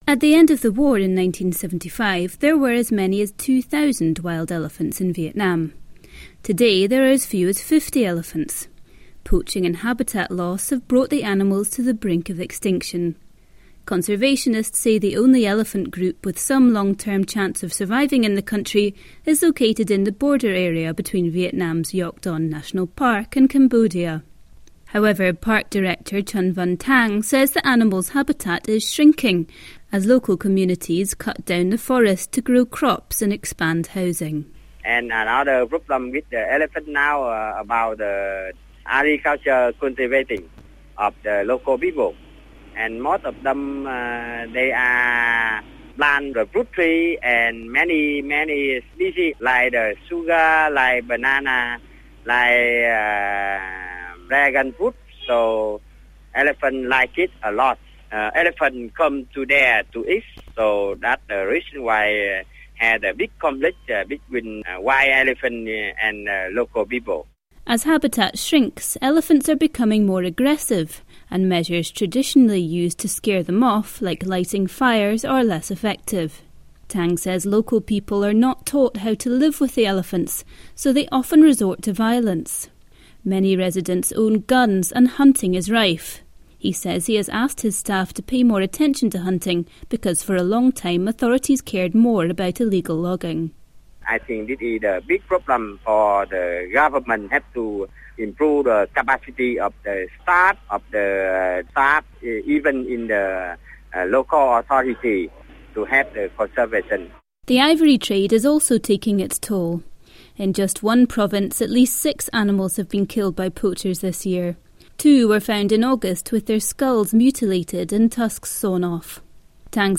Listen to report on poaching in Vietnam